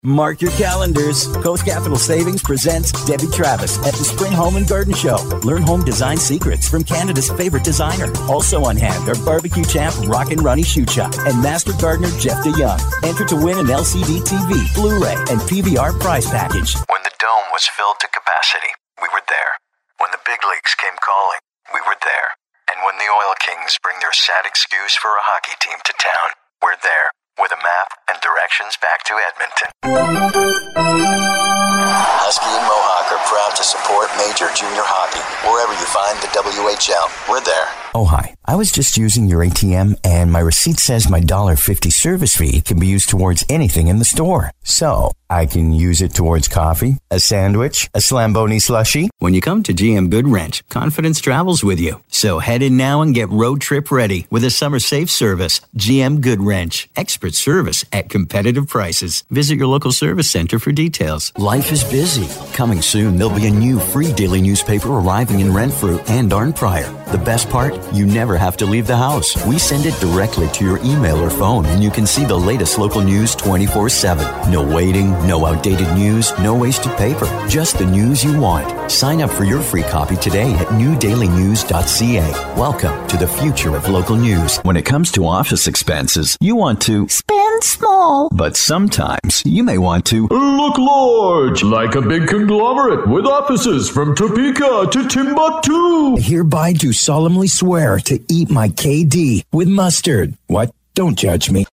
I'm a full-time Canadian male voice talent with my own professional home studio in Vancouver Canada.
Sprechprobe: Werbung (Muttersprache):
My vocal arsenal runs the gamut from the guy next door to wry to selected character and ethnic voices. I deliver a natural and believable read that engages the intended target audience and gets your product/service and brand the attention it deserves!